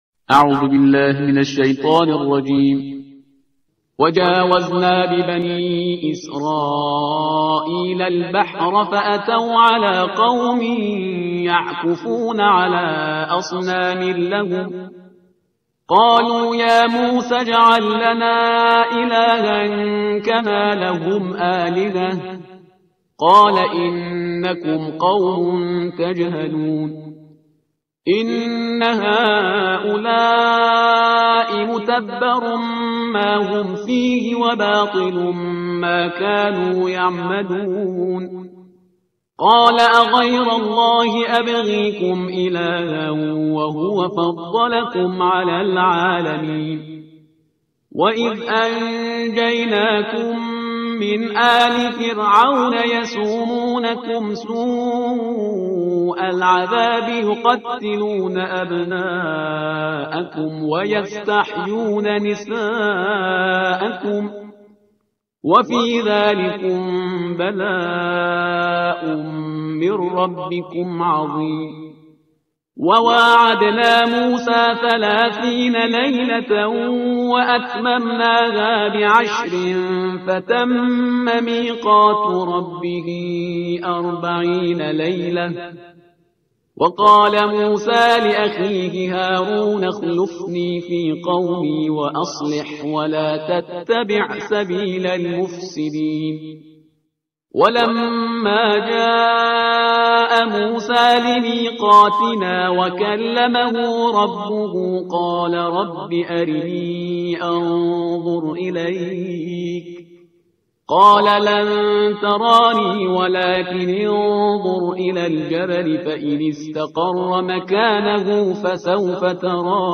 ترتیل صفحه 167 قرآن با صدای شهریار پرهیزگار